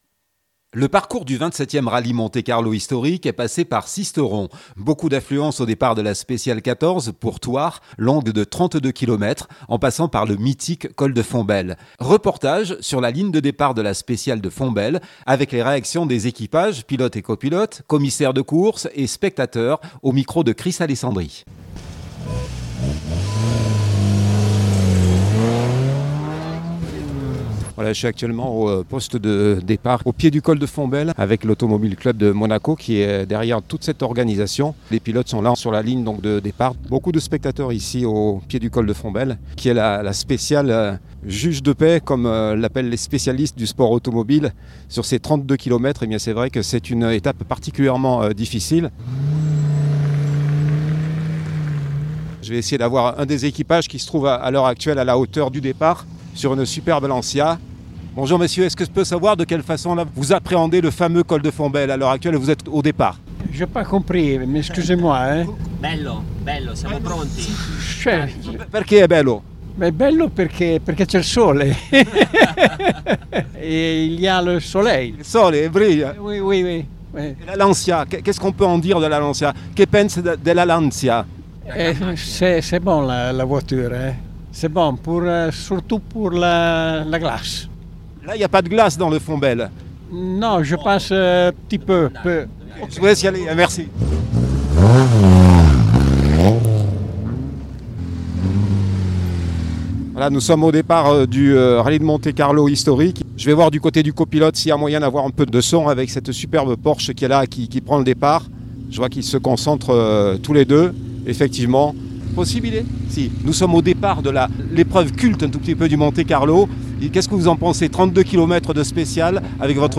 2025-02-06 - Sisteron-Rallye Monte Carlo Hisdtorique.mp3 (14.28 Mo) Le parcours du 27e rallye Monte-Carlo historique, est passé par Sisteron. Beaucoup d’affluence au départ de la spéciale 14, pour Thoard, longue de 32 kilomètres, en passant par le mythique col de Fontbelle, 233 voitures, toutes anciennes participantes du rallye Monte-Carlo: Alpine turbo, Porsche Carrera , Lancia Stratos ou bien encore la SM Mazeratti.